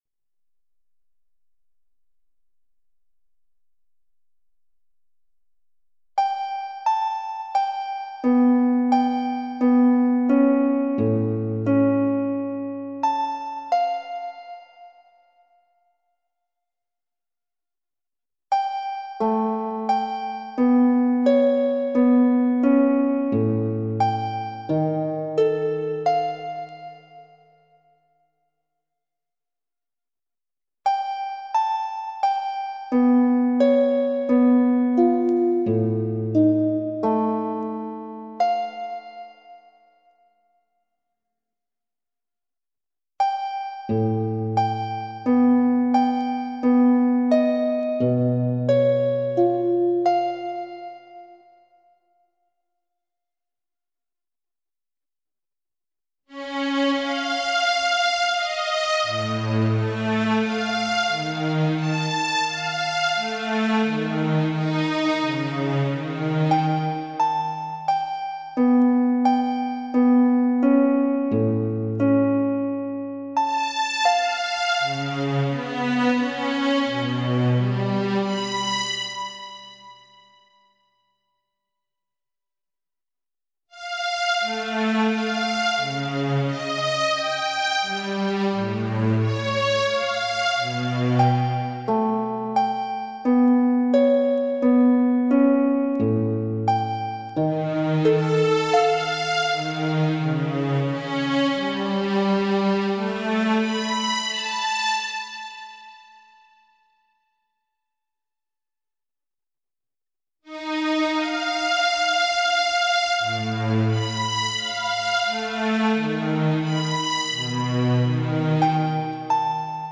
The calcium binding sites are played by harp, the flanking alpha helical regions are played by a string ensemble and the introductory and linking regions are played by flute. The second helix of each calcium binding domain begins within calcium binding site, so you’ll hear a brief overlap of harp and strings.